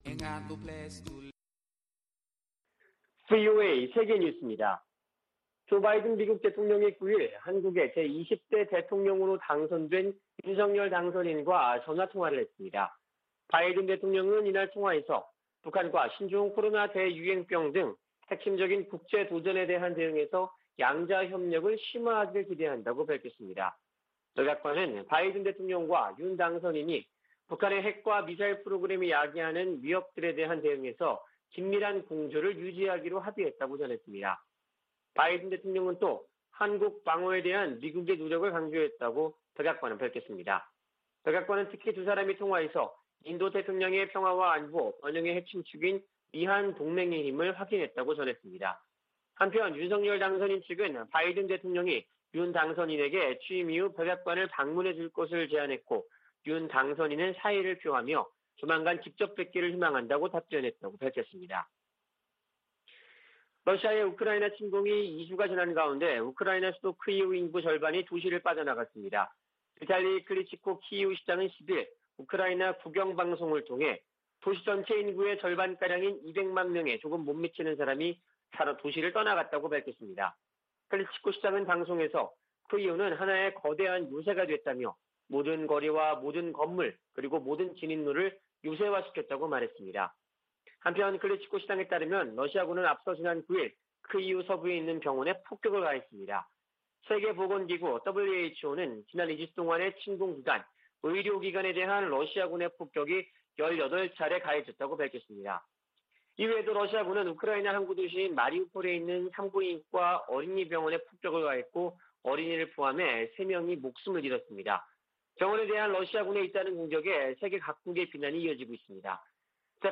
VOA 한국어 아침 뉴스 프로그램 '워싱턴 뉴스 광장' 2022년 3월 11일 방송입니다. 한국 대통령 선거에서 윤석열 후보가 승리했습니다.